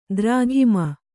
♪ drāghima